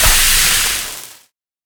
gold_blowout.ogg